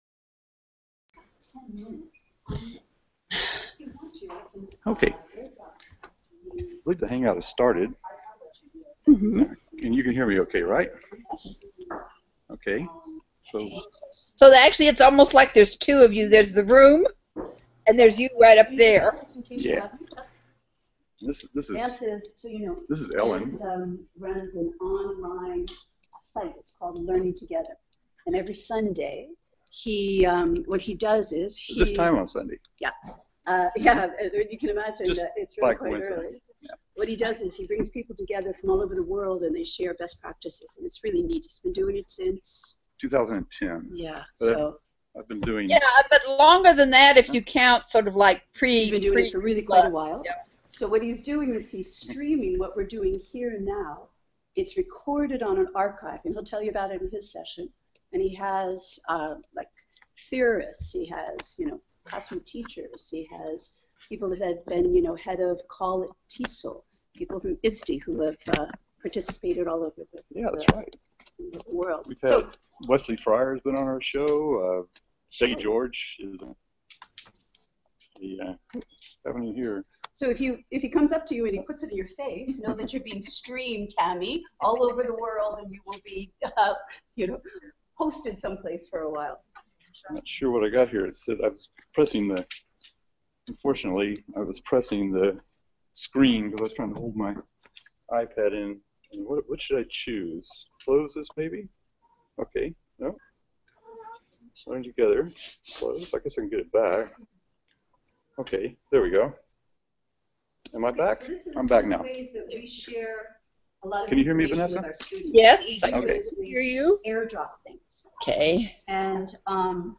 Learning2gether about iPadagogy live from ISTE Philadelphia
learning2gether-about-ipadagogy-live-from-the-iste-conference-philadelphia-_jpx1rzzwb8.mp3